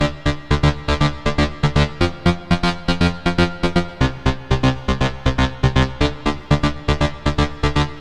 loops basses dance 120 - 2
Téléchargez et écoutez tous les sons et loops de basses style dance music tempo 120bpm enregistrés et disponibles sur les banques de sons gratuites en ligne d'Universal-Soundbank pour tous les musiciens, cinéastes, studios d'enregistrements, DJs, réalisateurs, soundesigners et tous ceux recherchant des sons de qualité professionnelle.